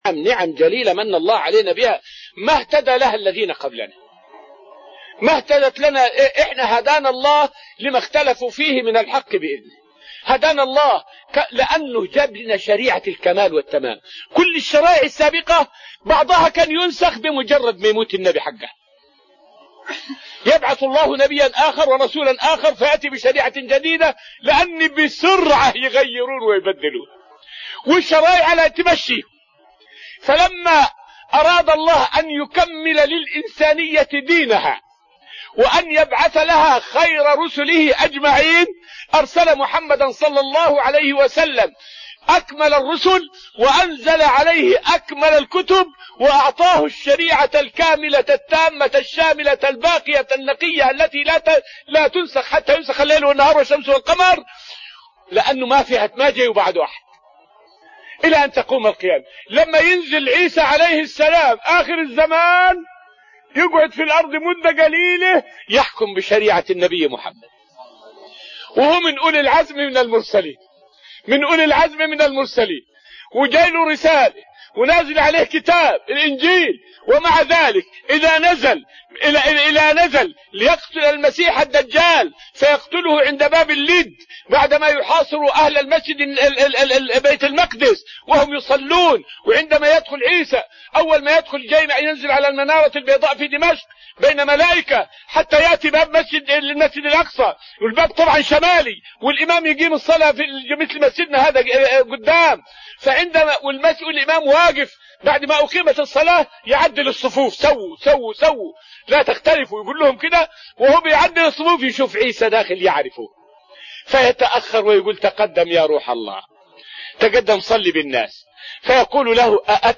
فائدة من الدرس العاشر من دروس تفسير سورة الأنفال والتي ألقيت في رحاب المسجد النبوي حول فضل الشريعة المحمدية على شرائع الأنبياء السابقة.